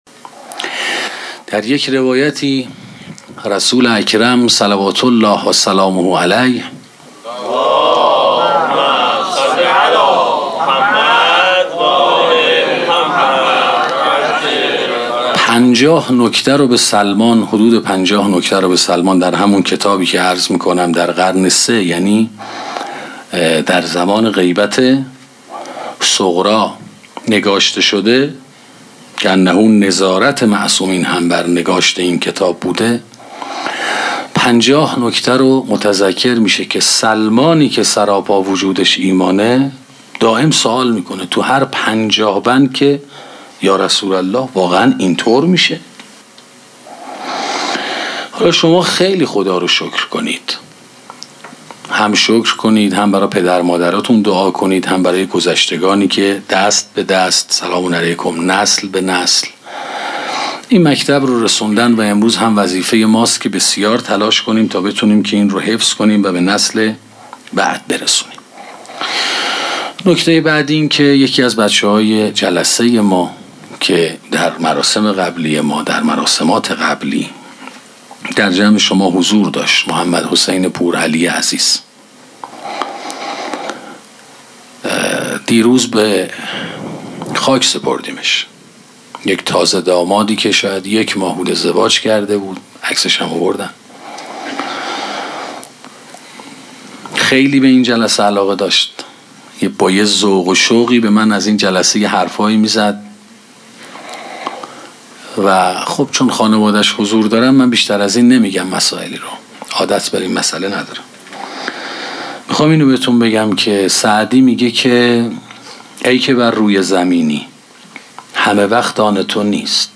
سخنرانی خانواده و تربیت فرزند 1 - موسسه مودت